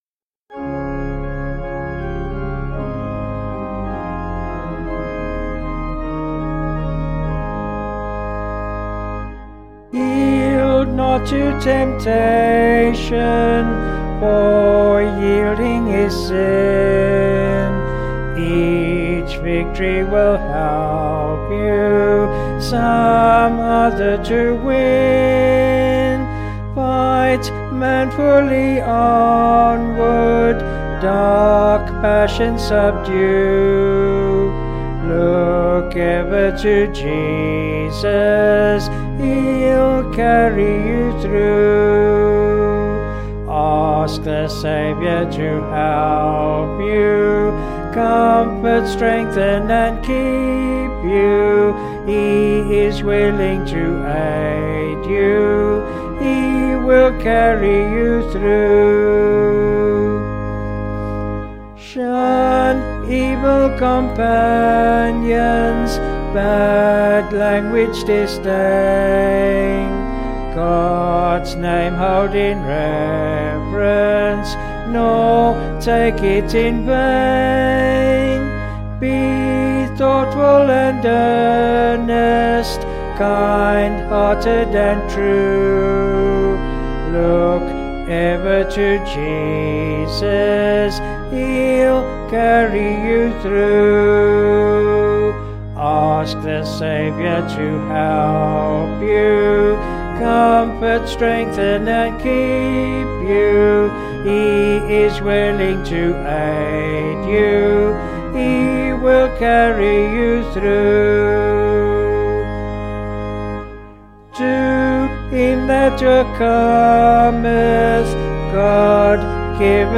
Vocals and Organ   263.6kb Sung Lyrics